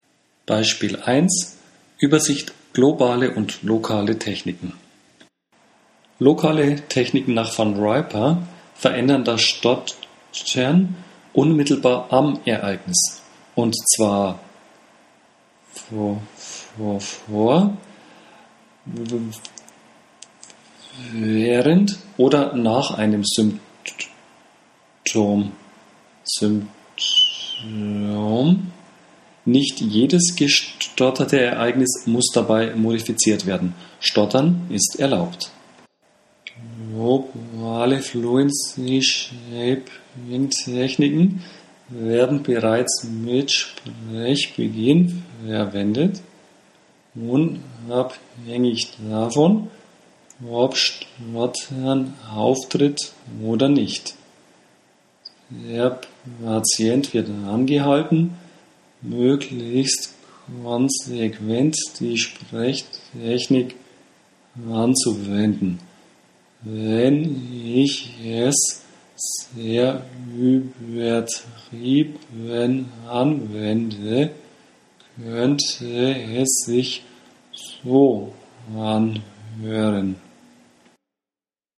Sprechtechniken in der Stottertherapie. Übersicht über gängige Sprechtechniken mit Audio-Beispielen